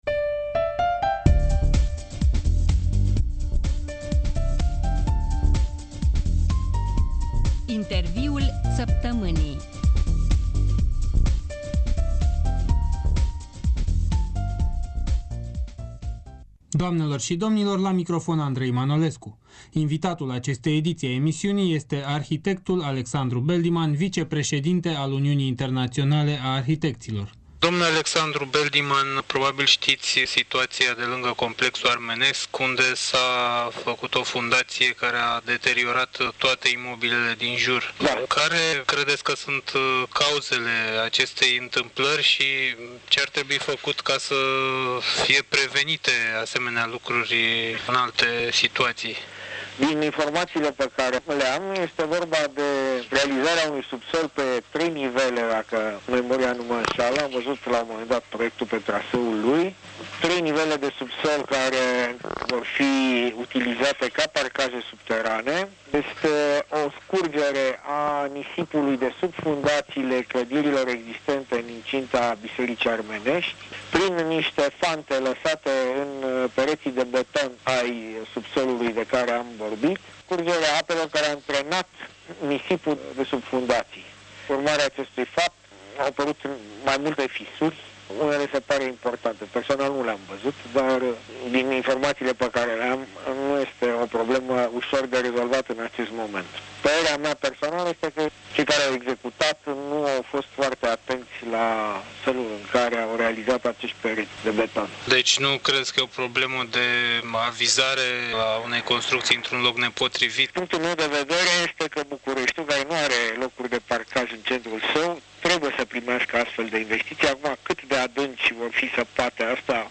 Interviul săptămînii: cu arh.